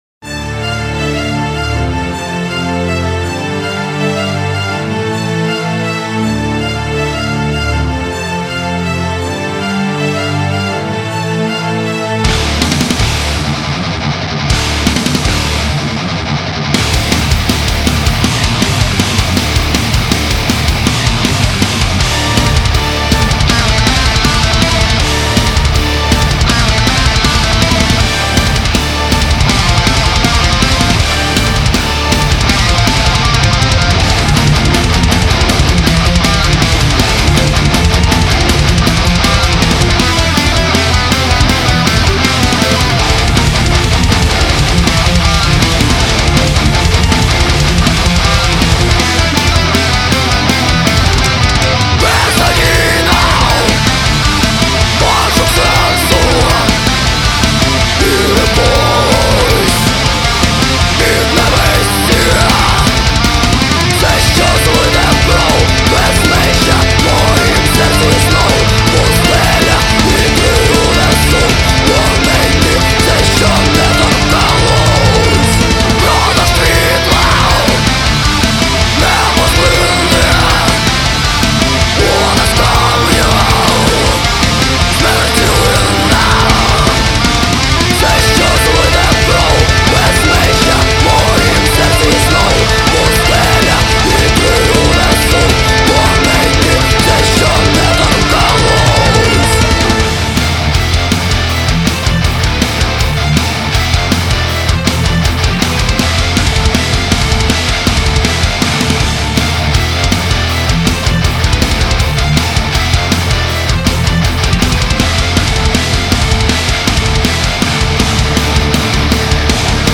вокал
гитара (соло)
барабаны
бас
гитара (ритм)
клавиши